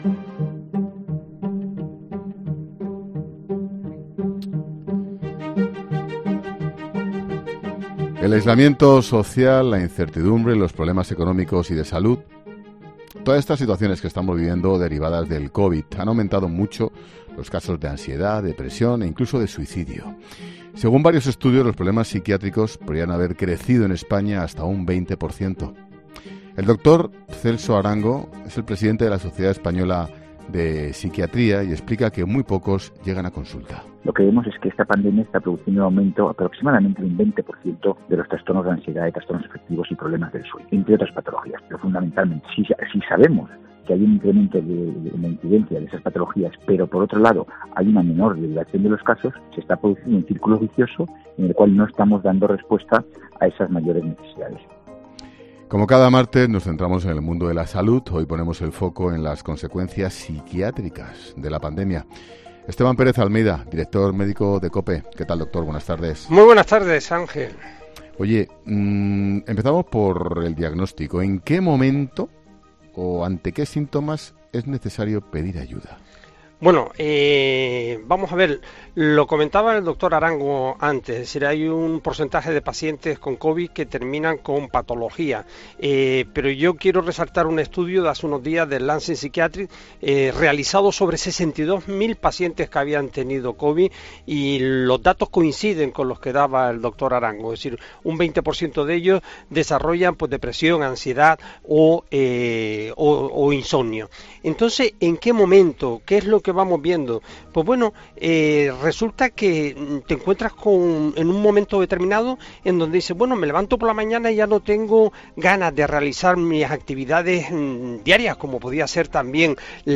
Ángel Expósito enciende cada día La Linterna de 19 a 23.30 horas.